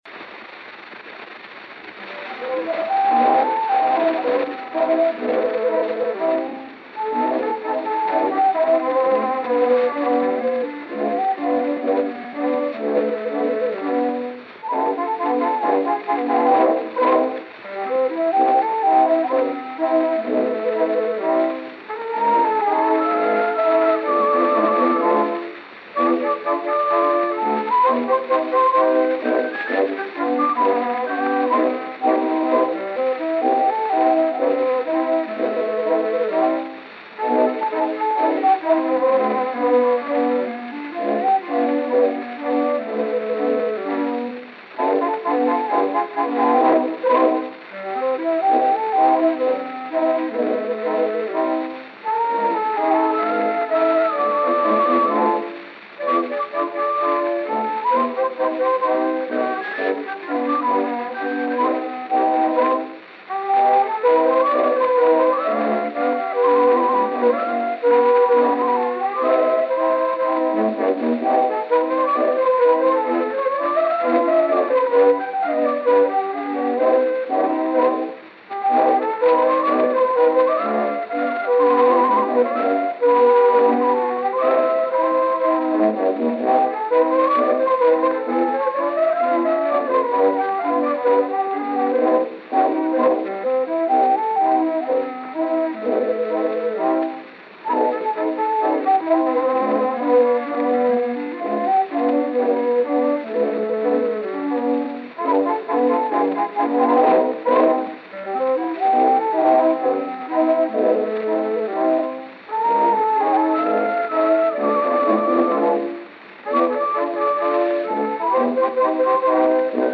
Disco de 78 rotações, também chamado "78 rpm", gravado em apenas um lado e com rótulo "rosa".
A performance da gravação foi executada pelo "Grupo Cahyense".